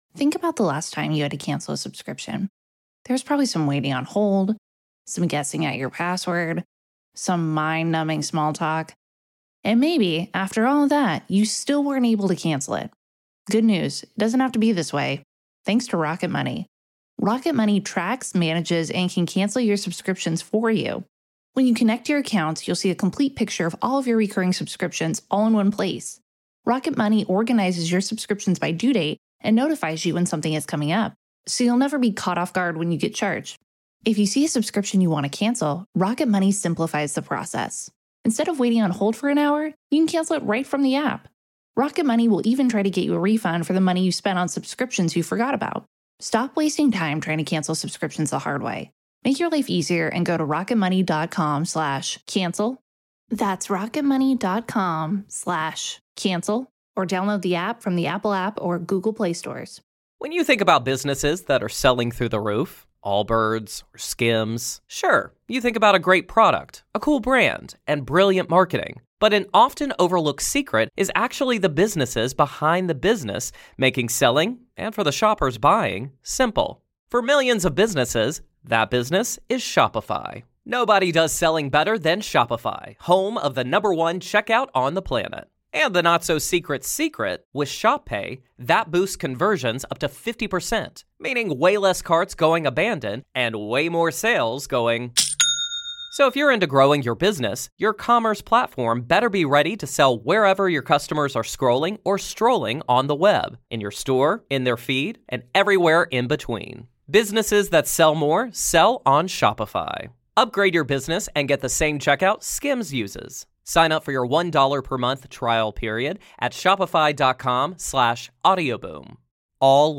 The Good Listening To Show is the Desert Island Discs of UKHR. This feel-good Storytelling Show that brings you ‘The Clearing’.
The King of Improv Neil Mullarkey from ‘The Comedy Store Players’, talking about his new Good Book, ‘In The Moment’ LIVE from Bath Theatre Royal - Part 1 (of 2)